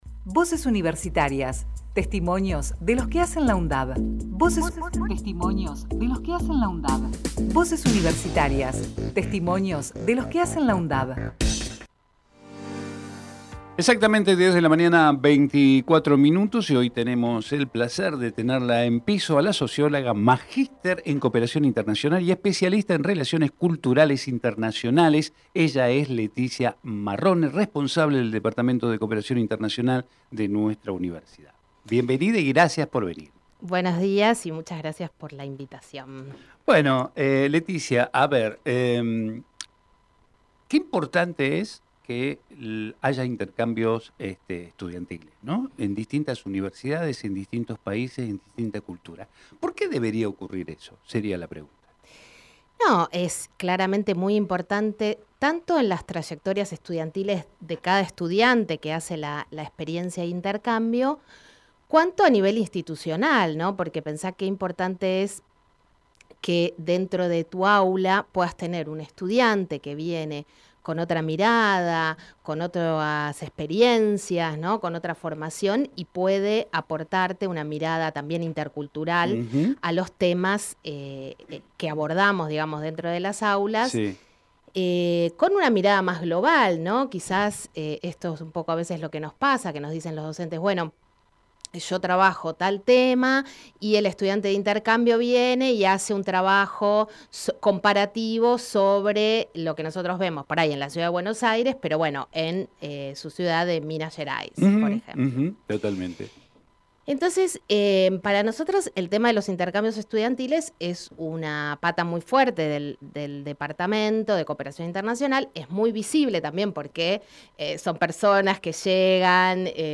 Enrevista